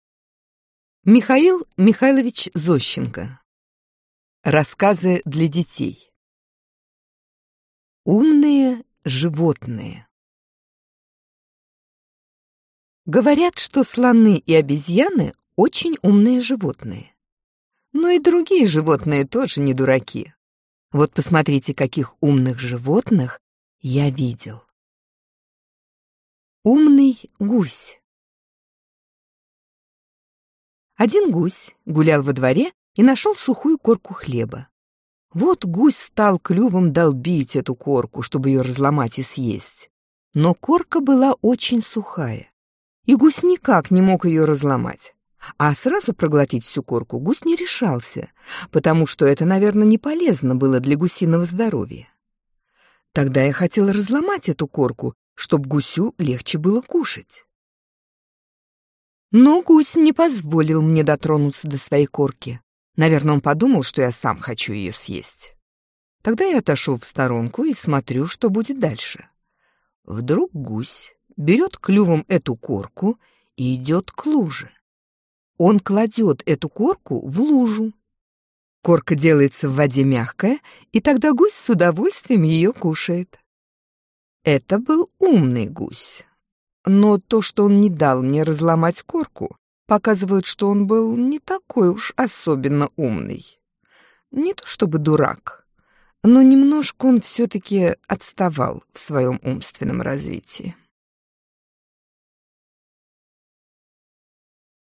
Аудиокнига Русские классики детям: Рассказы Михаила Зощенко | Библиотека аудиокниг